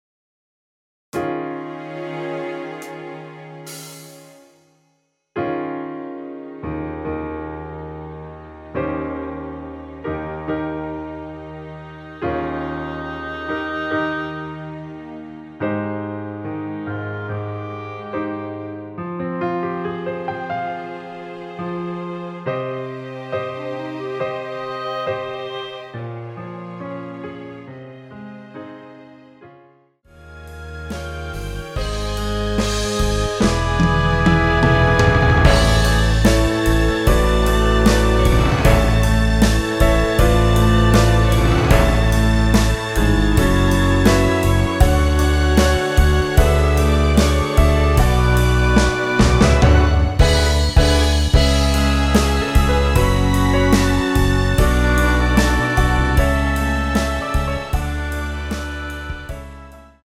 원키에서(+5)올린 MR 입니다.
G#
앞부분30초, 뒷부분30초씩 편집해서 올려 드리고 있습니다.
중간에 음이 끈어지고 다시 나오는 이유는
뮤지컬